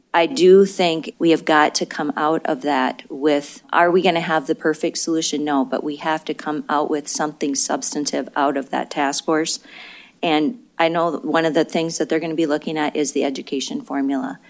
Republican Representative Karla Lems of Canton says an interim study committee has its work cut out for it.